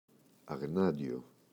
αγνάντιο [aꞋγnandʝo]